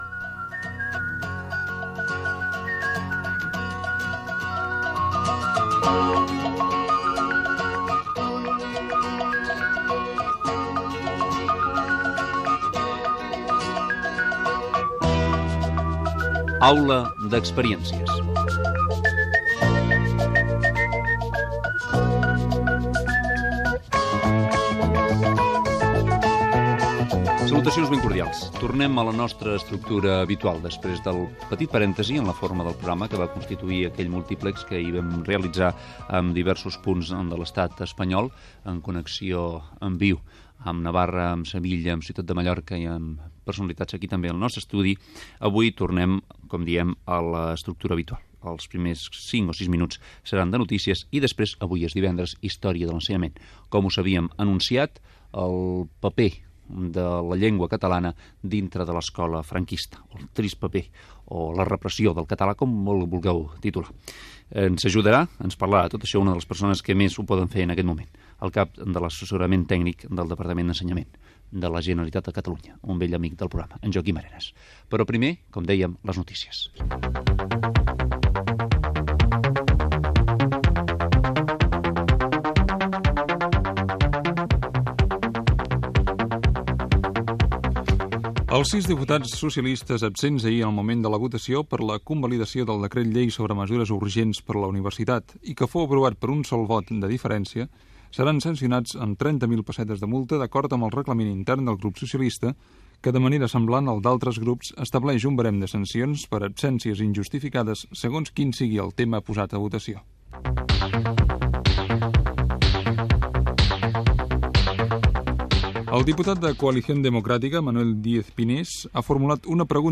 Presentació, sumari, notícies Espai dedicat a l'escola durant el franquisme, s'ha publicat el llibre "El llenguatge i l'escola".
Gènere radiofònic Divulgació